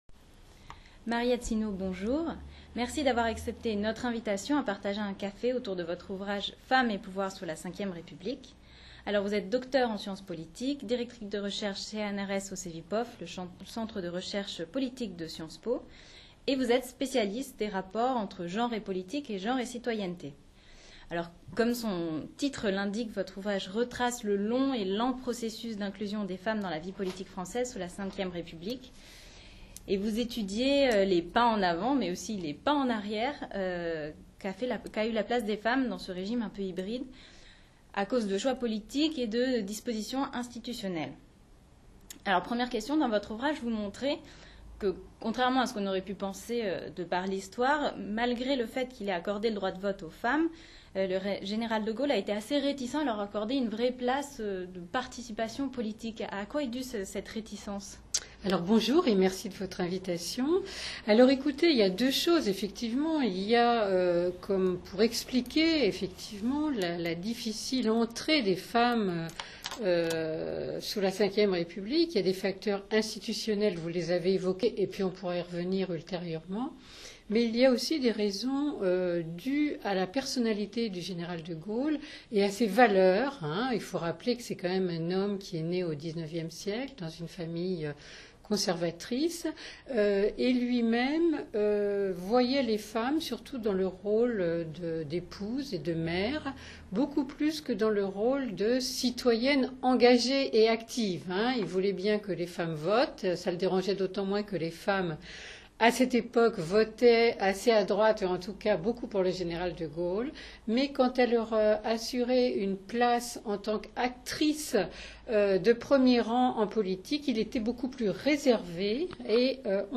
Le 22 novembre 2011 s’est tenu le 11ème Café de l’innovation politique dans les locaux de la Fondapol.